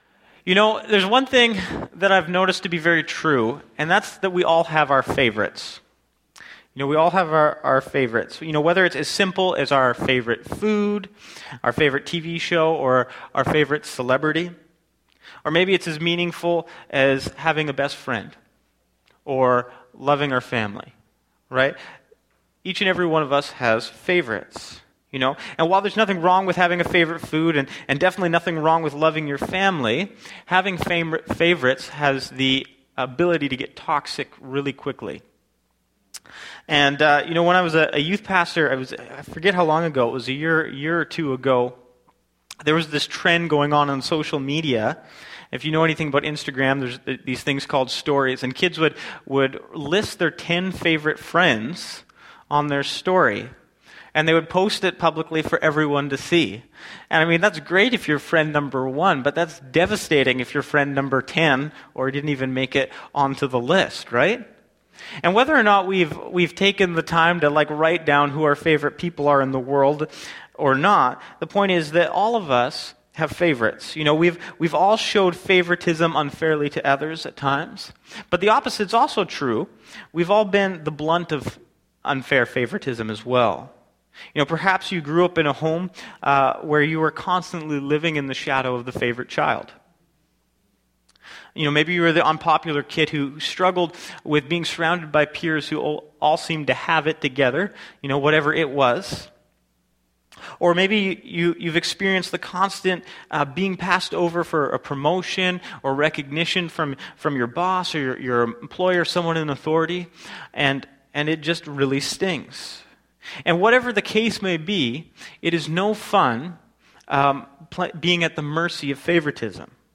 Sermons | Bethel Church Ladysmith